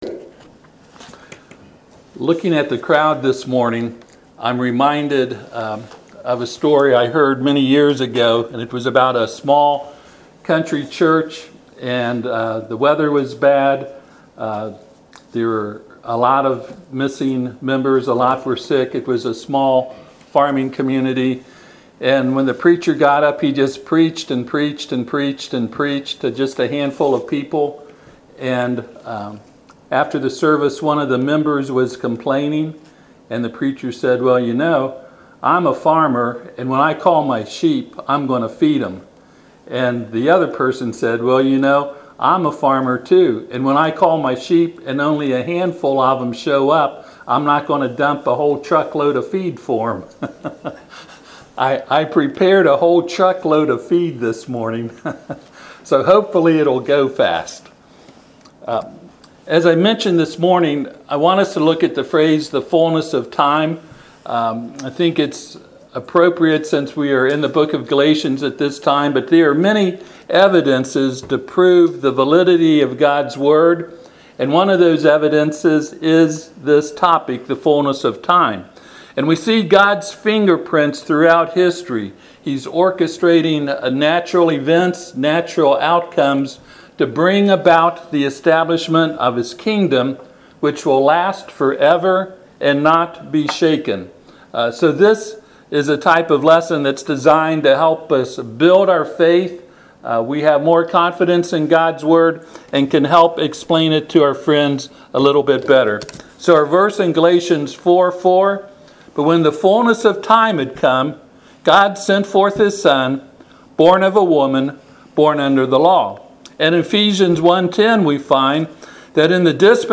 Galatians 4:4 Service Type: Sunday AM There are many evidences to prove the validity of God’s word.